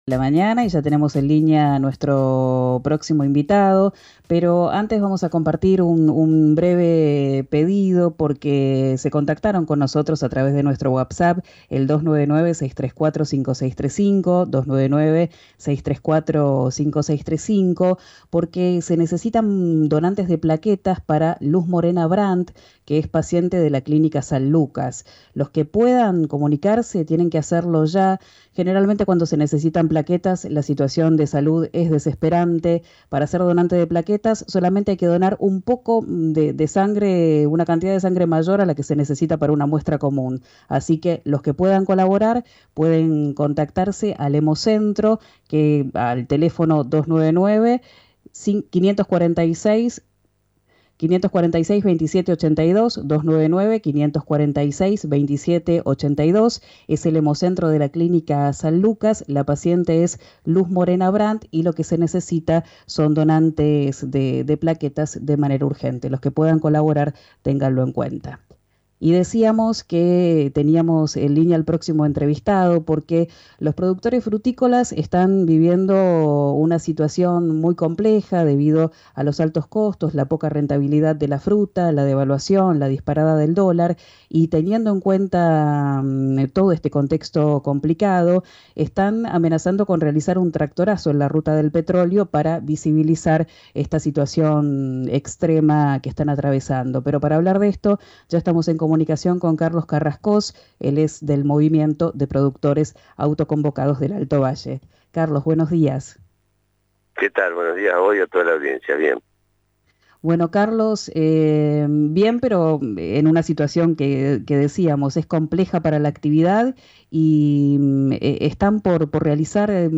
“El petróleo de Vaca Muerta termina siendo un invasor sobre el territorio del alto Valle, que ha sido históricamente el desarrollo de la actividad agropecuaria, en particular de la pera y manzana”, sostuvo en diálogo con RÍO NEGRO RADIO.